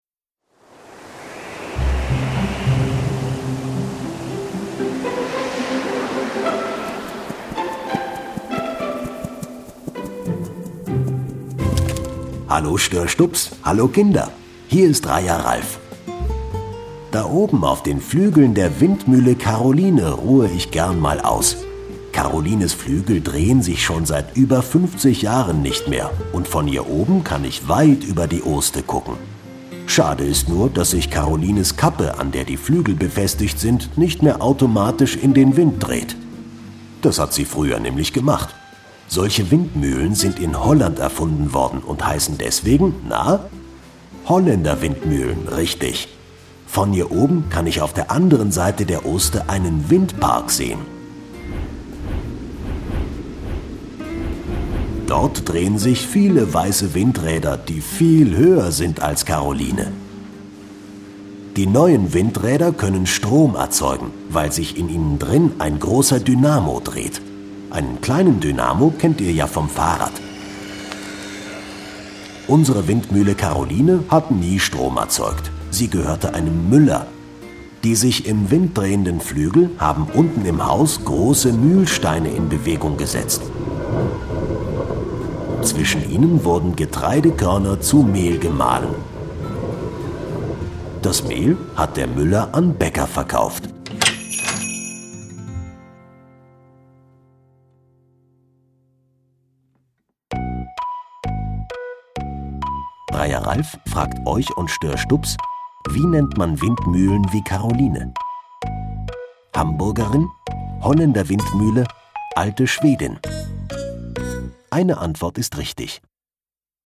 Windmühle Caroline - Kinder-Audio-Guide Oste-Natur-Navi